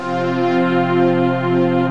PADY CHORD02.WAV